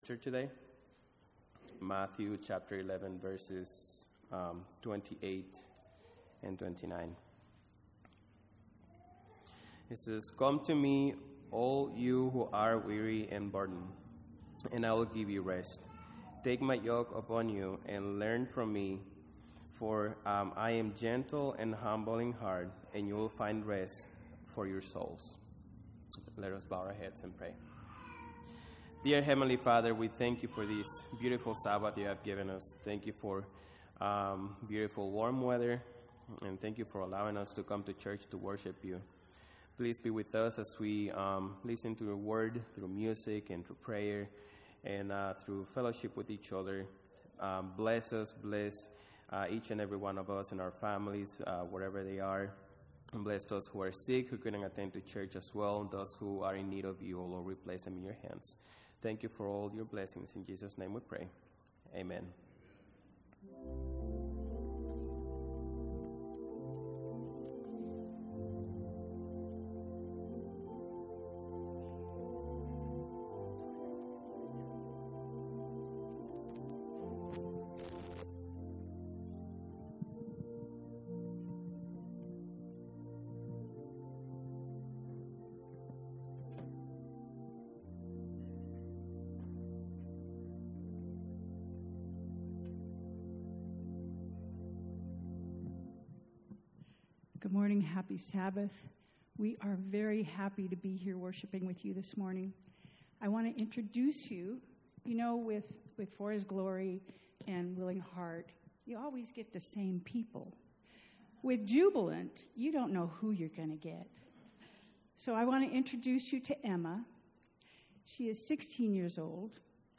The Spoken Word